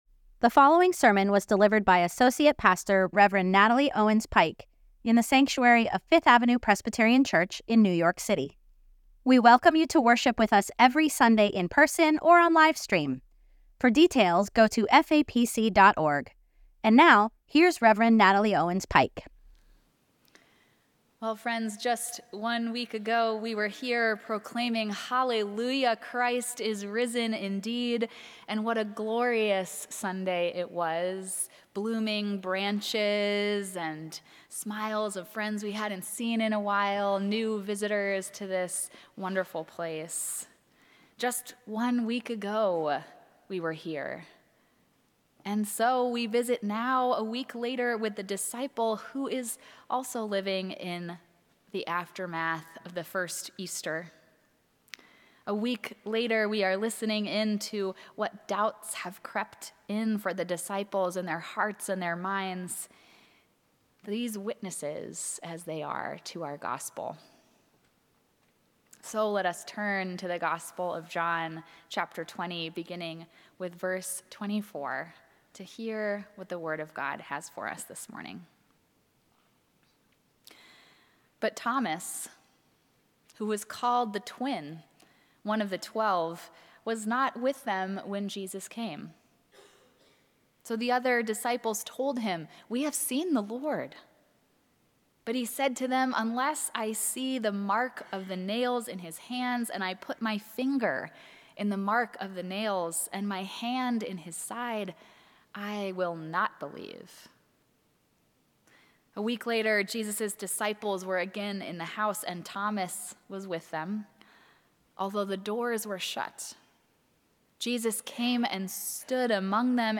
Sermons at FAPC